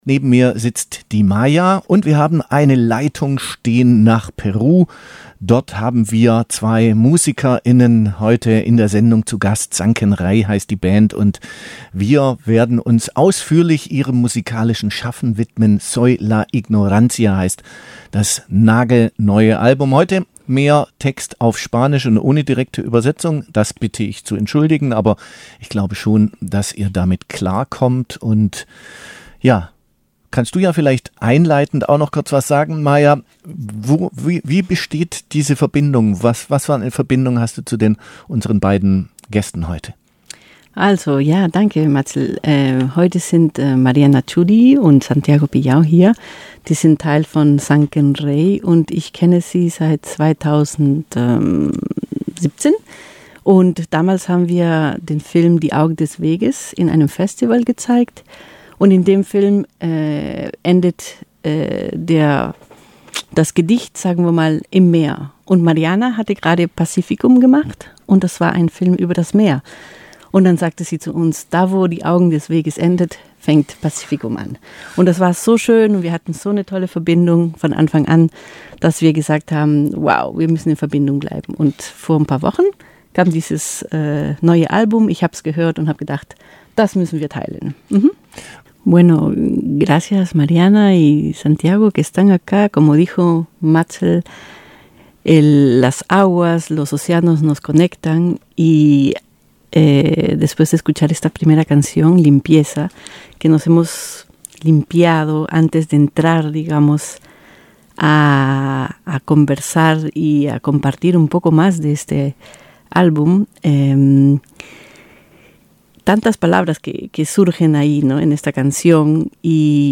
Sie haben im September 2023 ihr Album Soy La Ignorancia veröffentlicht und haben im Live-Interview ihre musikalischen und inhaltlichen Visionen mit uns geteilt.
92869_interview_sankenrei.mp3